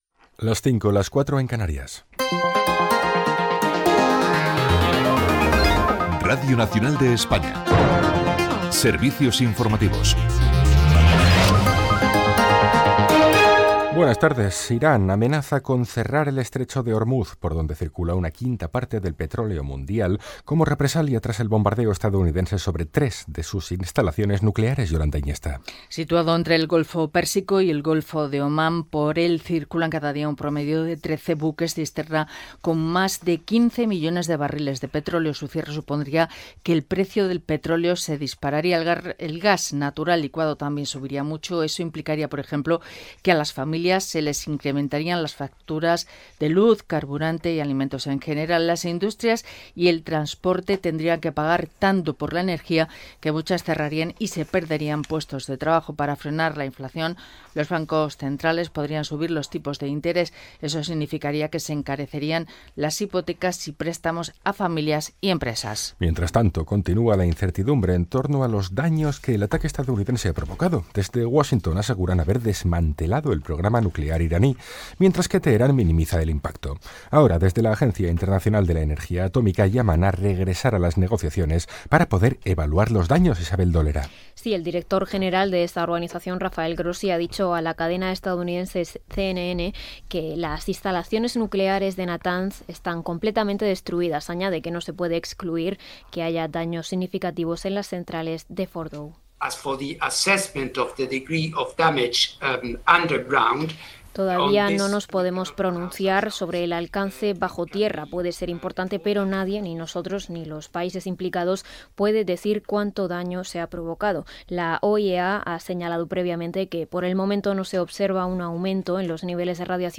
Hora, careta del programa, informació dels bombardaments dels Estats Units a l'Iran i del possible tancament de l’estret d’Ormuz per part de l'Iran, esports. Indicatiu de la ràdio
Informatiu